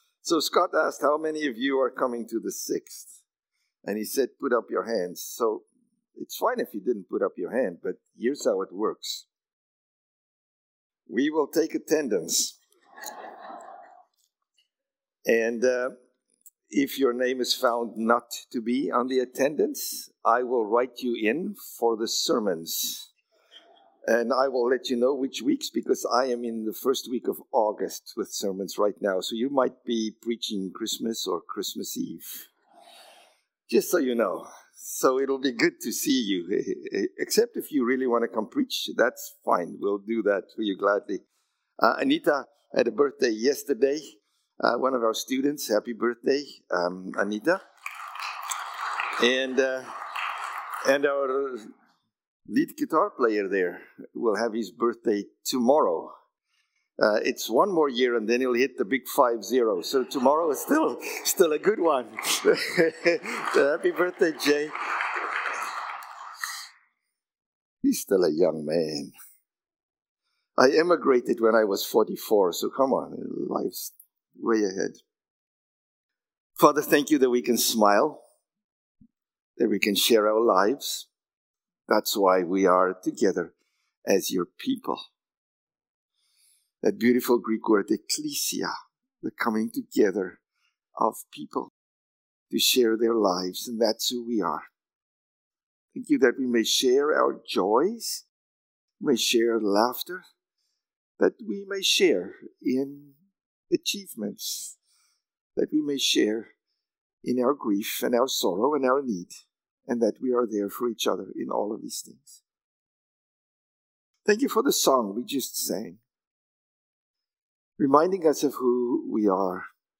March-23-Sermon.mp3